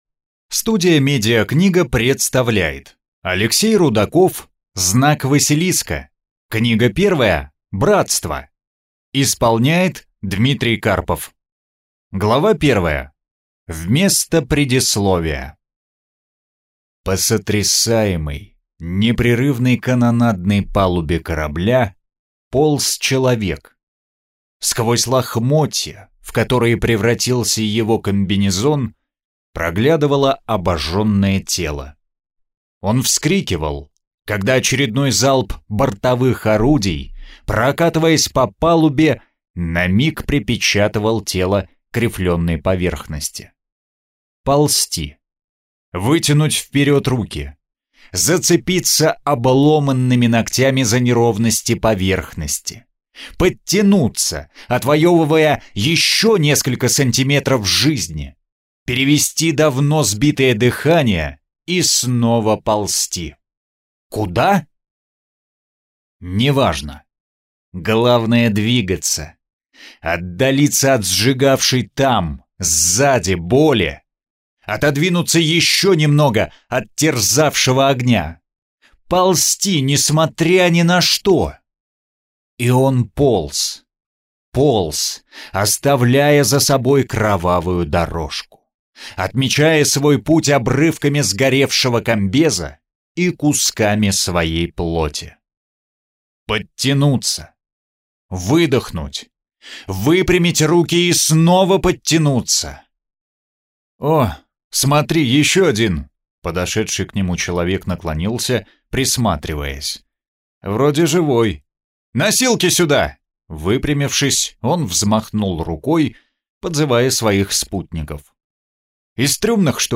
Аудиокнига Братство | Библиотека аудиокниг